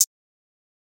Closed Hats
HHAT - PARTY.wav